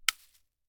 Bullet Shell Sounds
generic_leaves_1.ogg